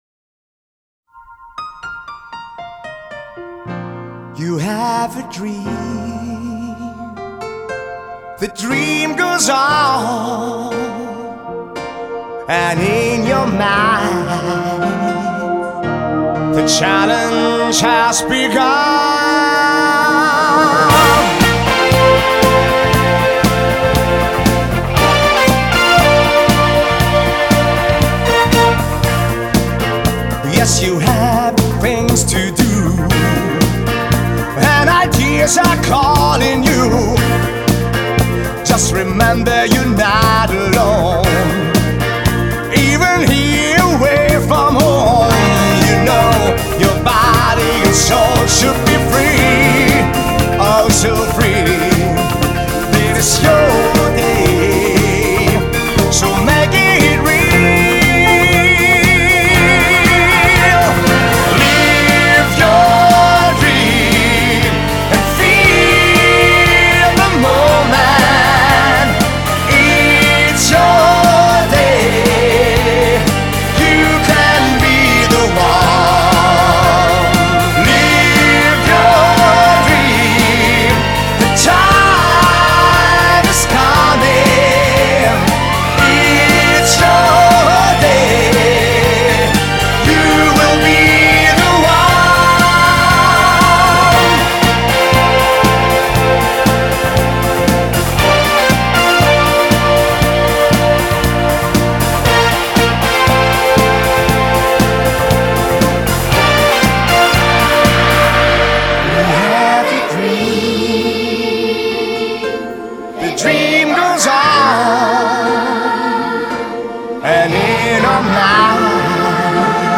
🎤 Produktion im Horus Sound Studio Hannover